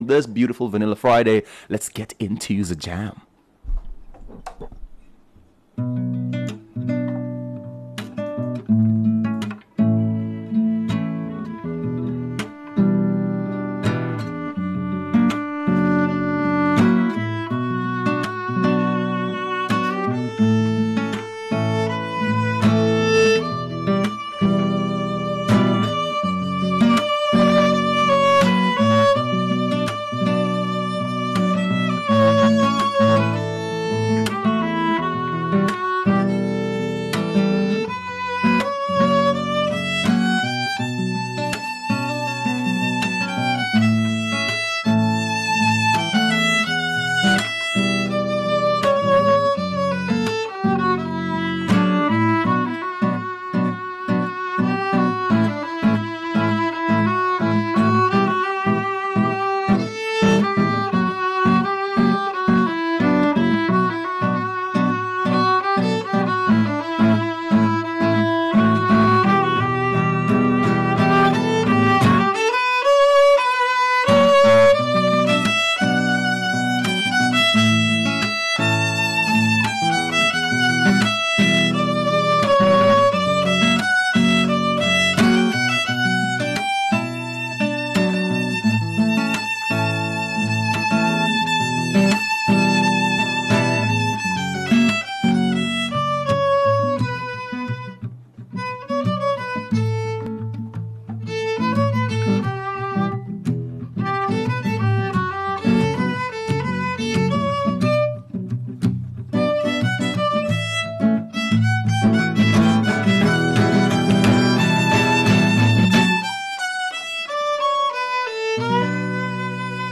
Violinist Live in Studio
Local violinist
live in studio.